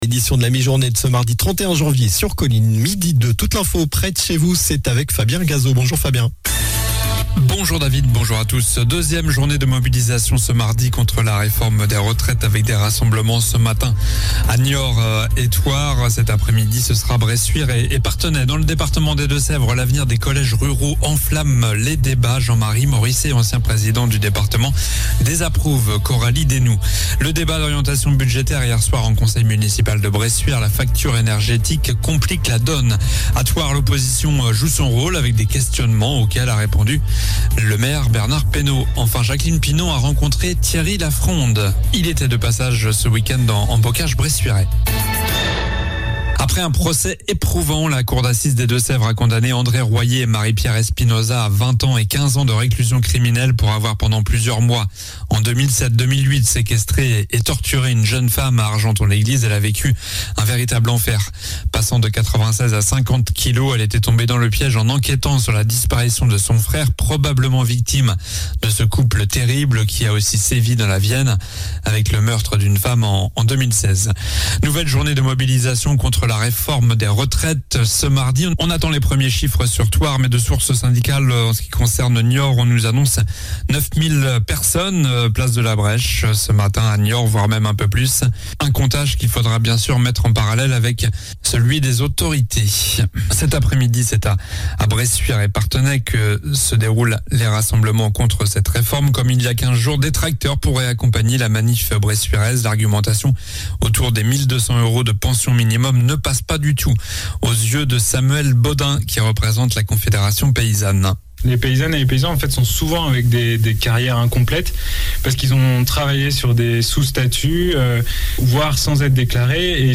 Journal du mardi 31 janvier (midi)